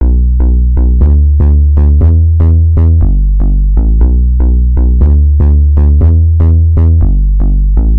An audio clip of a 2 bar bass riff repeated twice